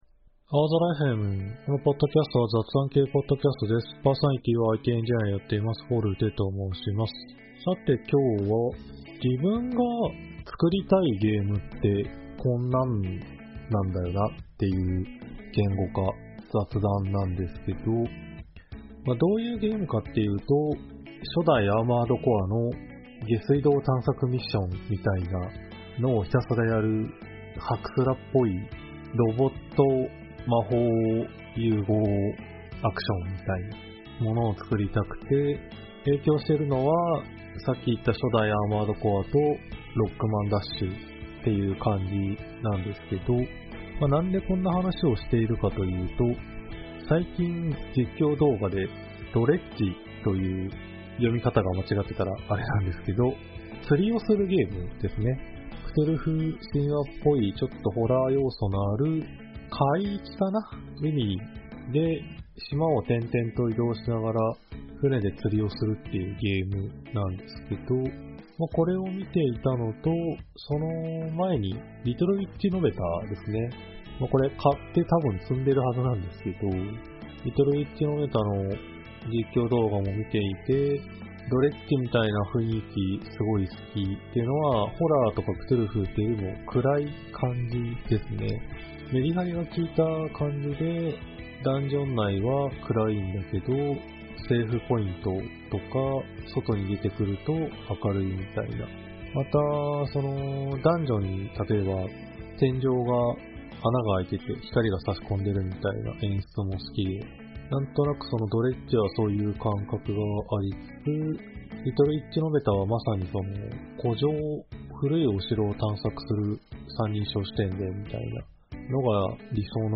aozora.fmは仕事や趣味の楽しさを共有する雑談系Podcastです。